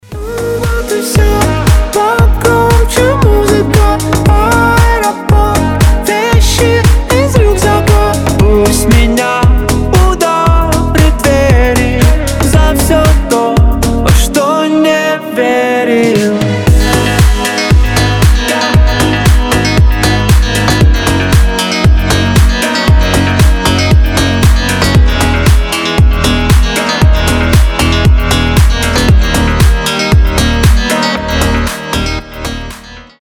• Качество: 320, Stereo
мужской голос